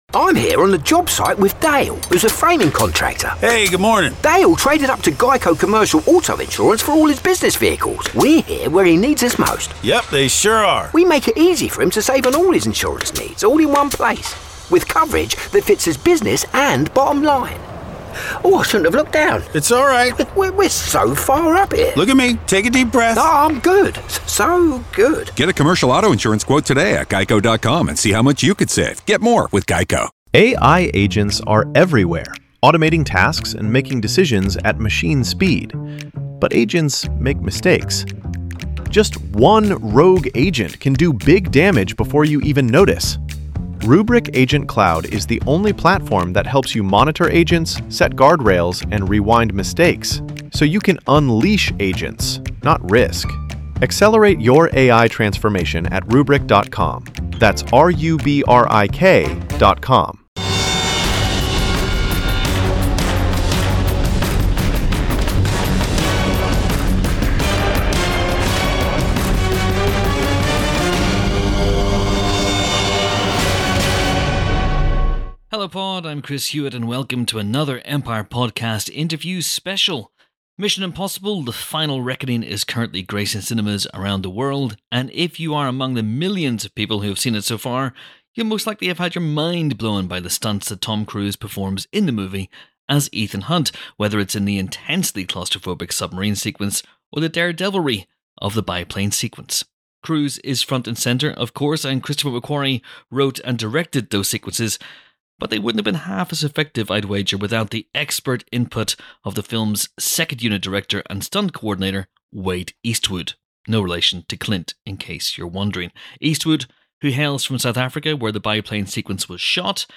An Empire Podcast Interview Special